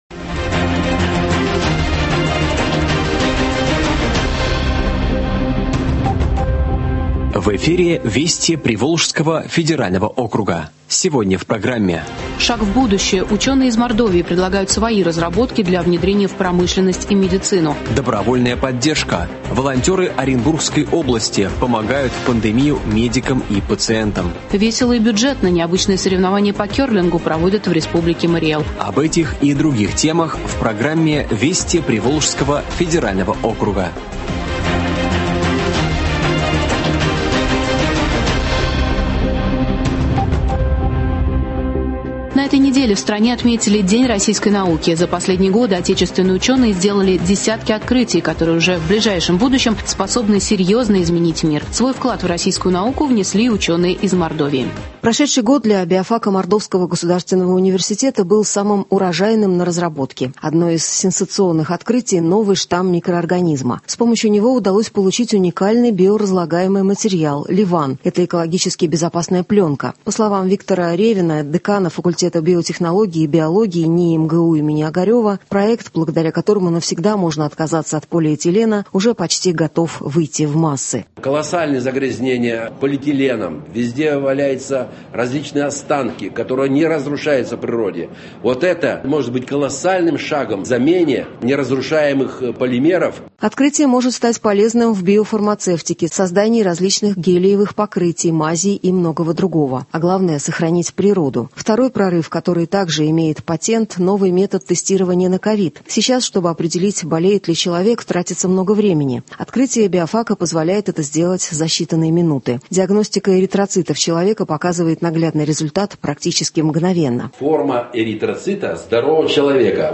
Радиообзор событий недели в регионах ПФО.